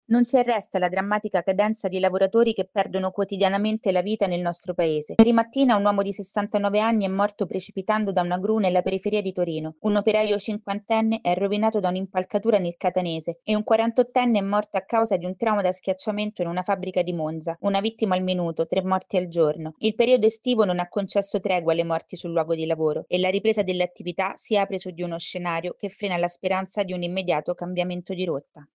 Nelle ultime ore il bilancio è peggiorato con nuovi gravi incidenti. Il servizio